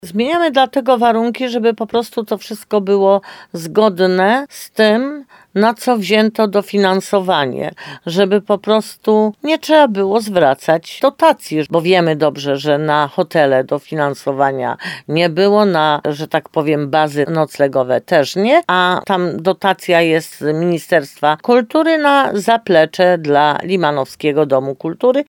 Burmistrz Jolanta Juszkiewicz tłumaczyła w rozmowie z RDN